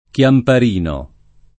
Chiamparino [ k L ampar & no ] cogn.